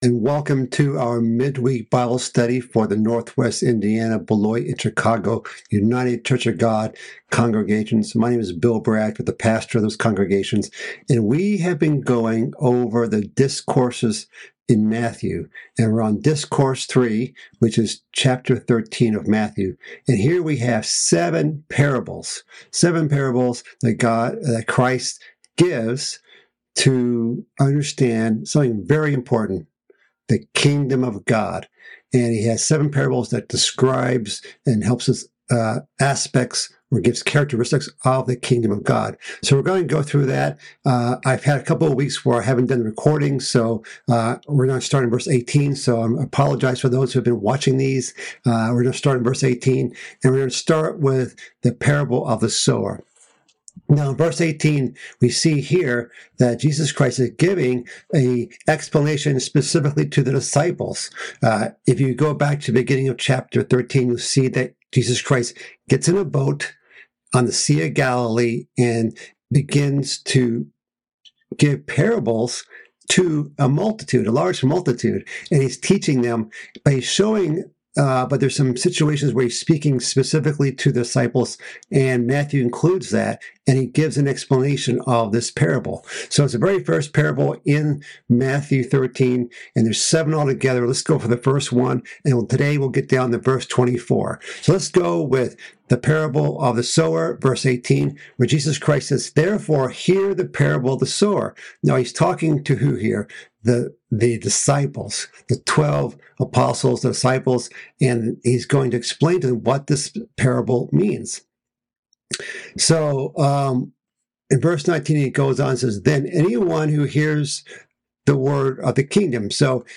This is a continuation of a mid-week Bible study series covering Christ's third discourse in the book of Matthew. This message continues in chapter 13 of Matthew, covering Christ's explanation of the parable of the sower.